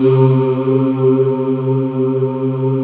Index of /90_sSampleCDs/USB Soundscan vol.28 - Choir Acoustic & Synth [AKAI] 1CD/Partition D/01-OUAHOUAH